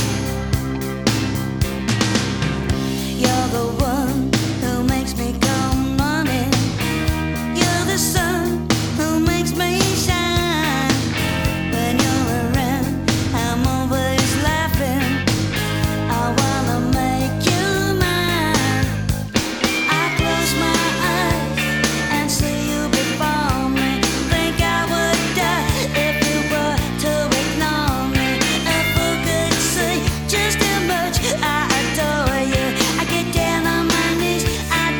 Жанр: Поп музыка / Рок / Альтернатива